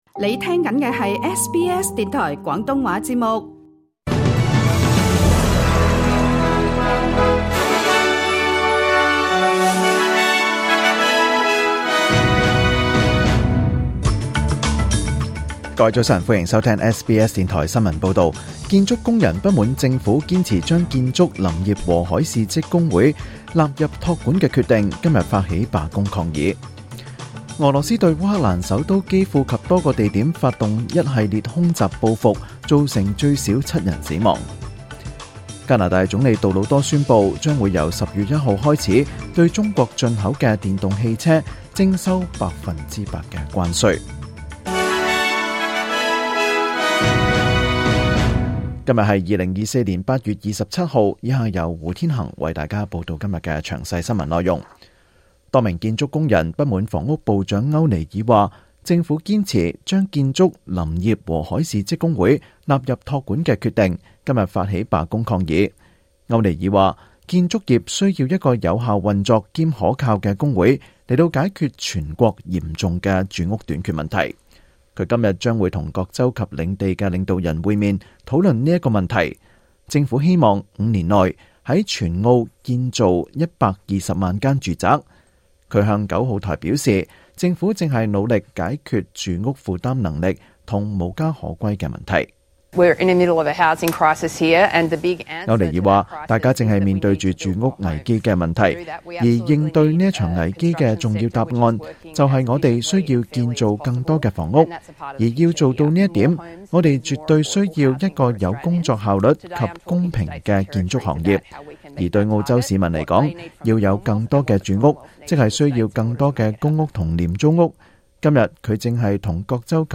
2024年8月27日SBS廣東話節目詳盡早晨新聞報道。